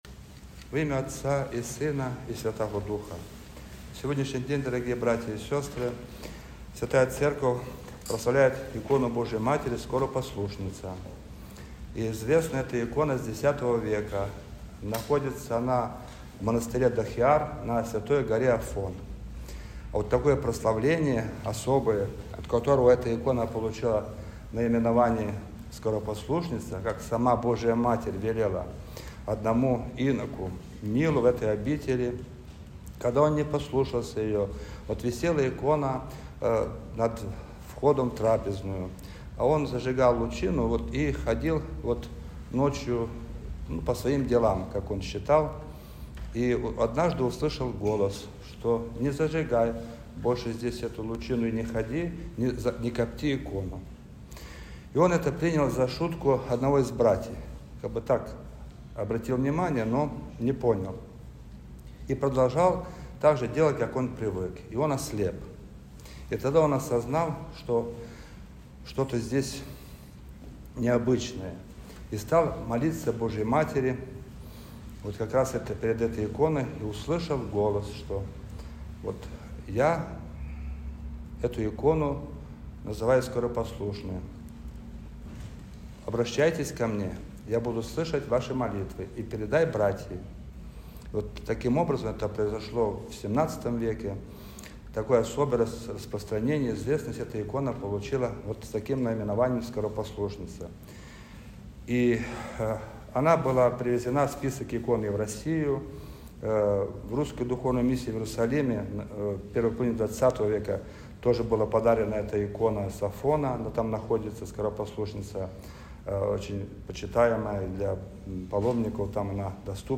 Проповедь-настоятеля.mp3